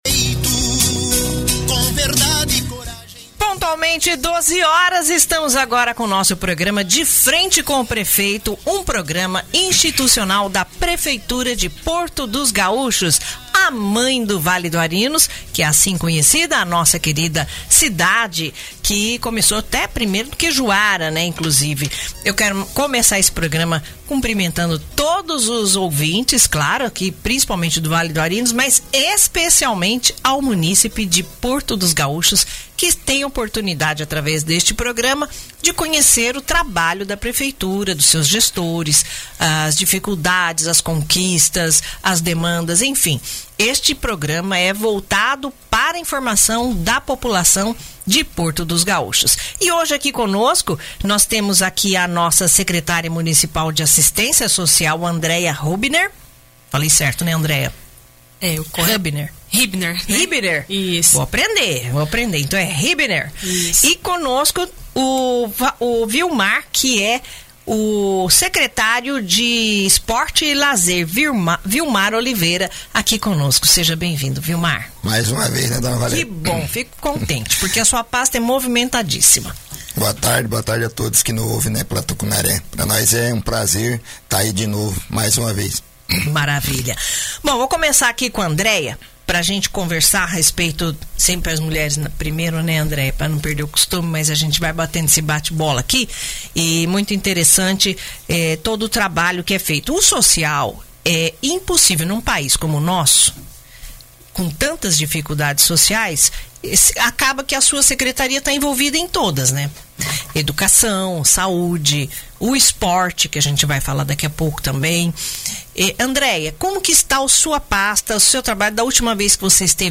Na última sexta-feira, 15 de agosto, o programa institucional “De Frente com o Prefeito”, transmitido ao meio-dia pela Rádio Tucunaré, contou com a participação da secretária de Assistência Social, Andréia Hubner, e do secretário de Esporte e Lazer, Vilmar Oliveira. Durante a entrevista, ambos apresentaram um panorama das principais atividades e projetos em andamento no município.